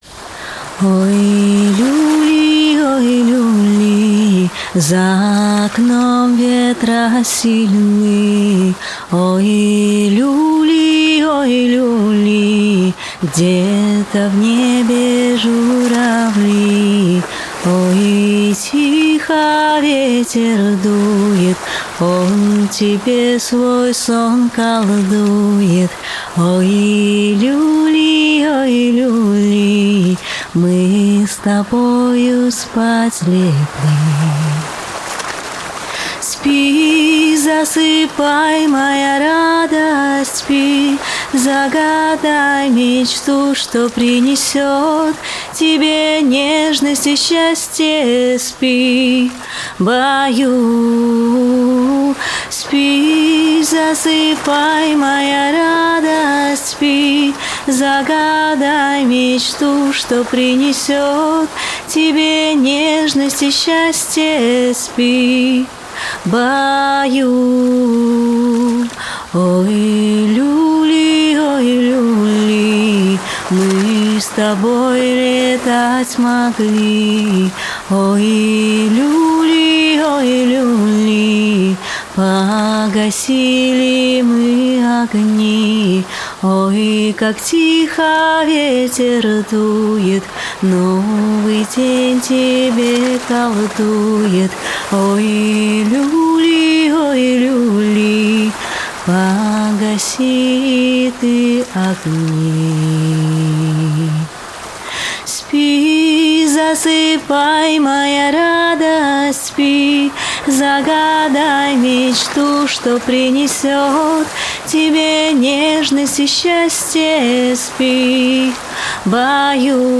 🎶 Детские песни / Колыбельные песни